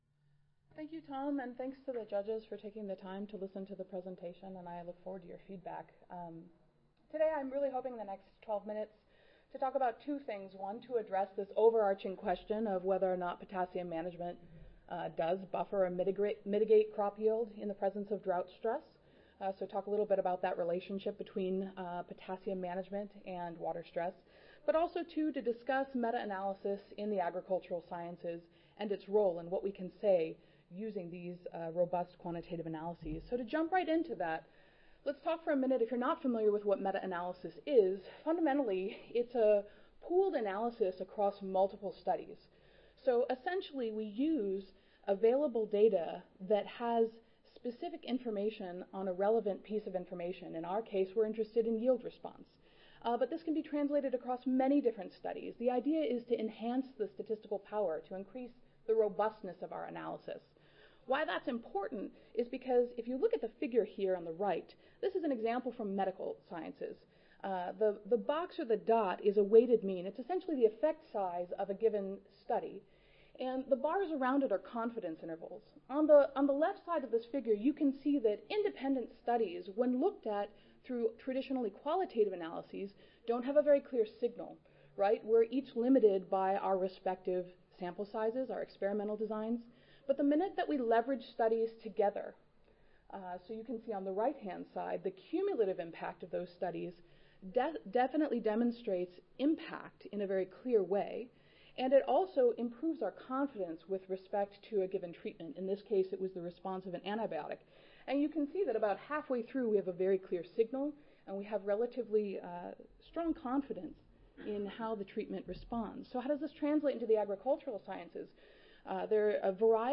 See more from this Division: SSSA Division: Soil Fertility and Plant Nutrition See more from this Session: Ph.D. Oral Competition II